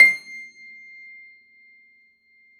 53f-pno22-C5.aif